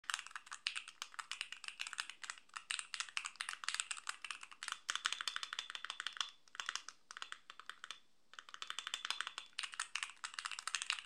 Każdy klawisz działa płynnie, bez nieprzyjemnego skrzypienia czy innych nieakceptowalnych dźwięków.
Sprzężenie zwrotne jest przyjemne, a dźwięk klawiszy stonowany.
Dobrze zbalansowana akustyka obudowy zwiększa komfort długiego pisania.